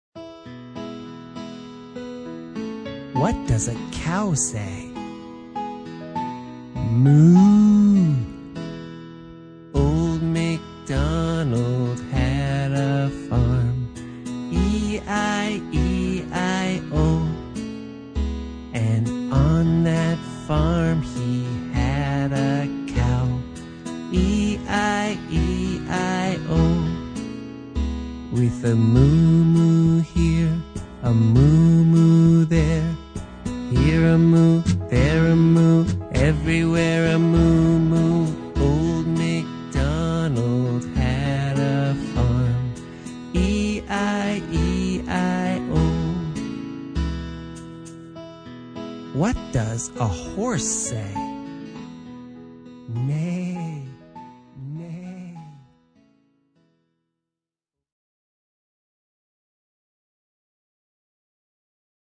Old Macdonald (slow) (slow)   ///